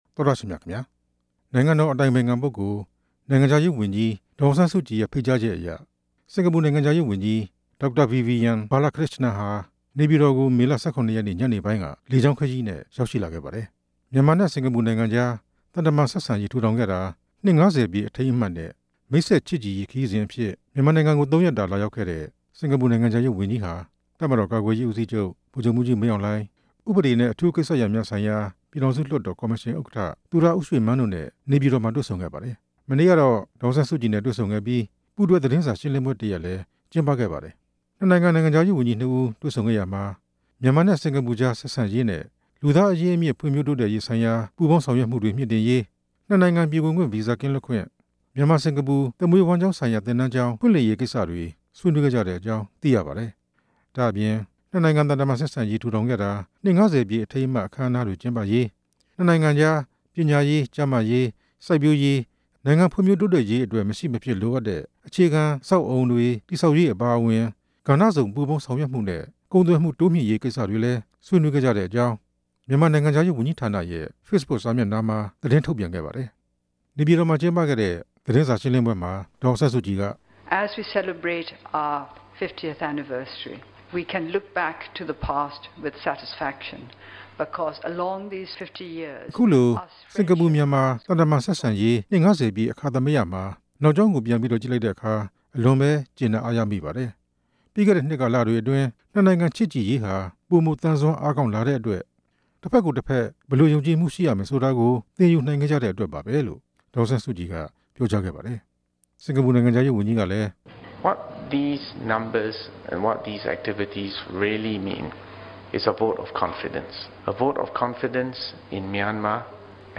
ဒေါ်အောင်ဆန်းစုကြည်နဲ့ စင်ကာပူနိုင်ငံခြားရေးဝန်ကြီး တို့ရဲ့ သတင်းစာရှင်းလင်းပွဲ